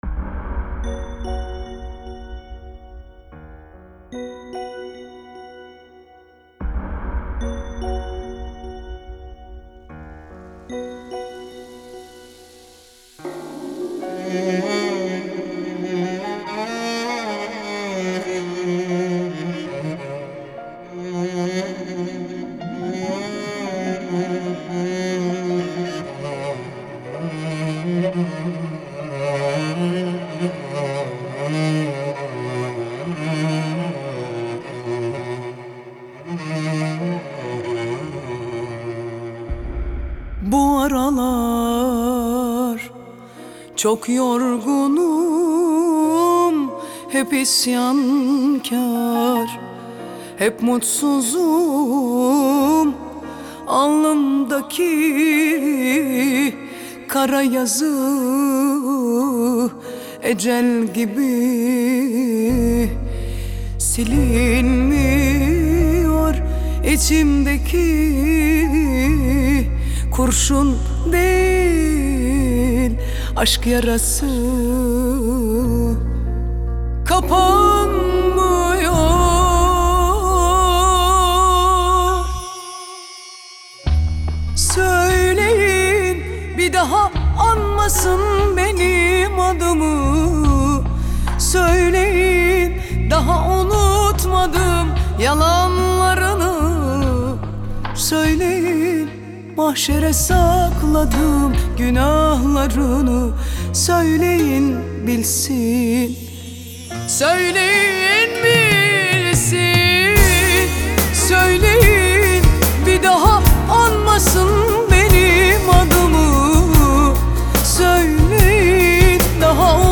آهنگ ترکیه ای
موسیقی پاپ